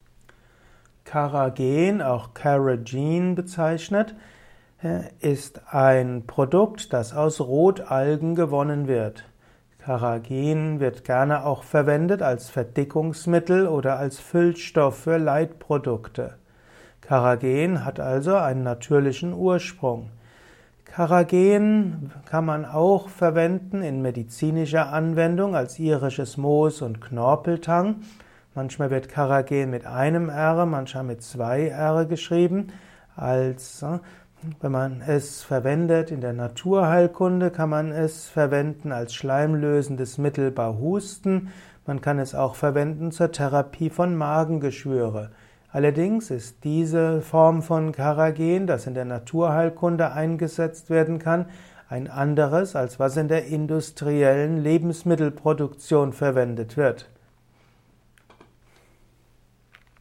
Erfahre Wissenswertes über Carrageen in diesem Kurzvortrag